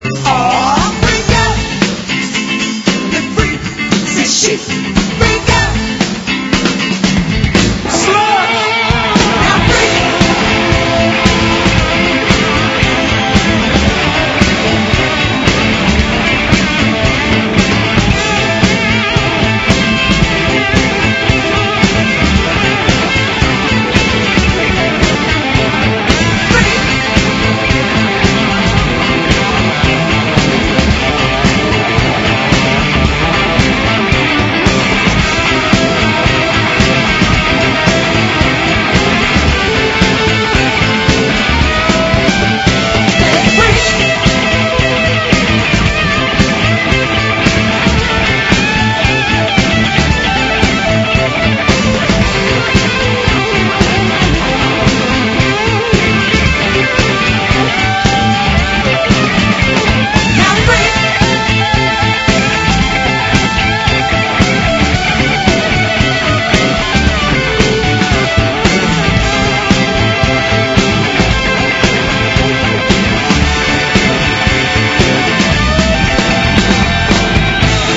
Leur qualité sonore a par ailleurs été réduite.